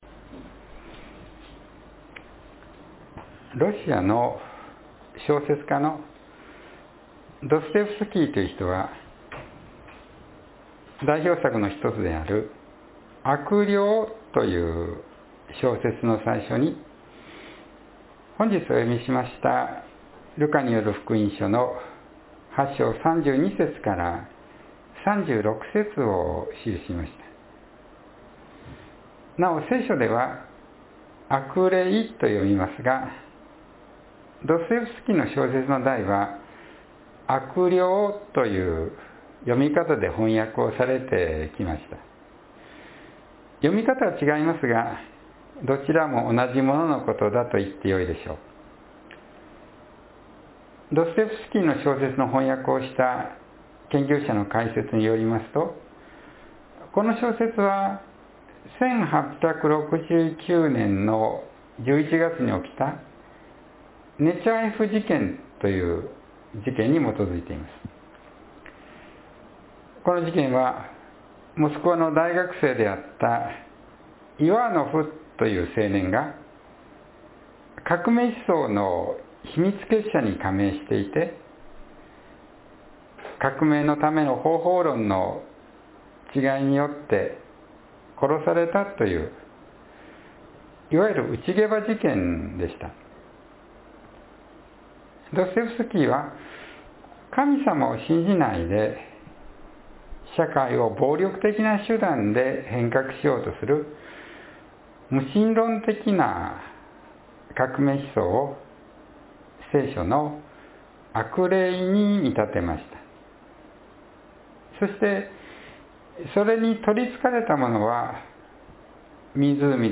（8月28日の説教より）